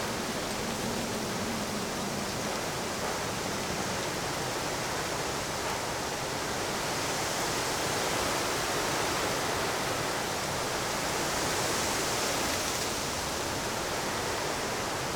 wind_in_trees.ogg